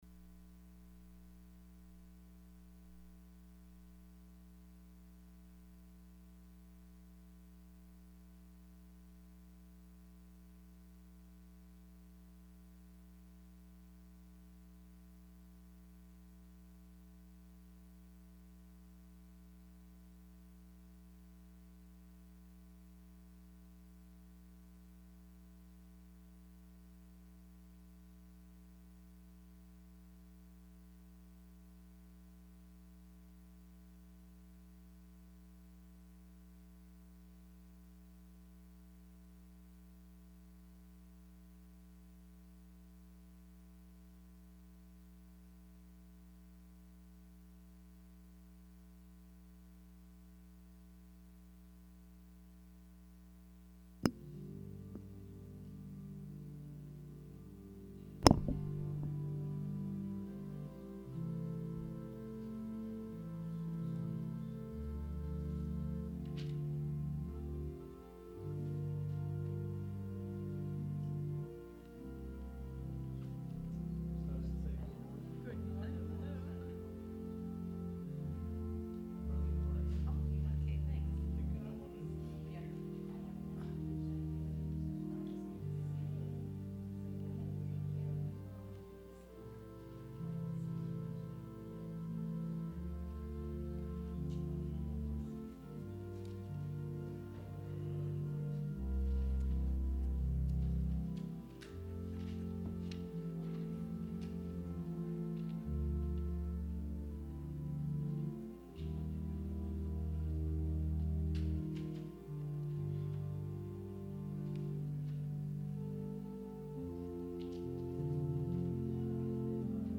Sermon – October 13, 2019